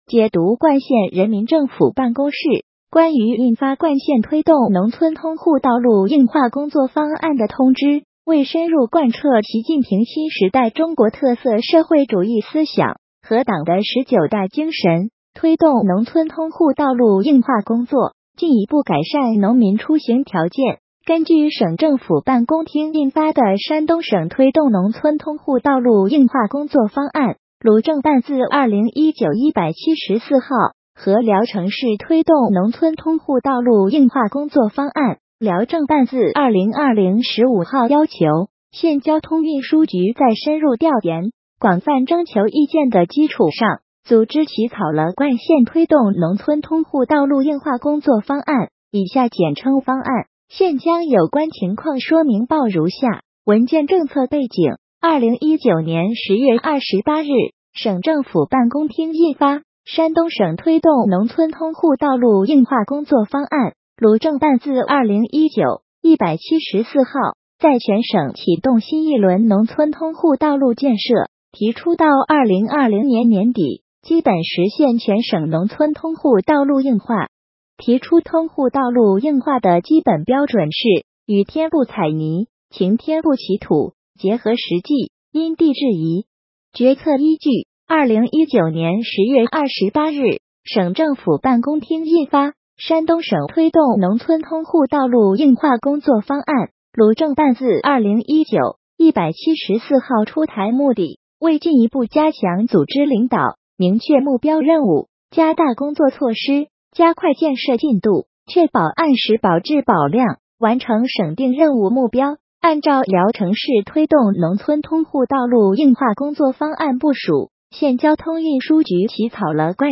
音频解读：《冠县人民政府办公室关于印发冠县推动农村通户道路硬化工作方案的通知》.mp3